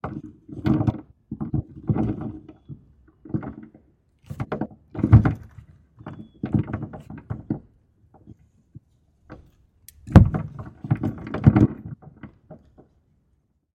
随机的 "链子粗的拨浪鼓钩在金属2深的底盘上
描述：链条厚的拨浪鼓挂在metal2 deep chassis.wav上
Tag: 底盘 d EEP 钩状 金属 拨浪鼓